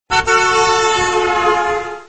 Bewegende auto met sirene
Speeding-car-horn_doppler_effect_sample.mp3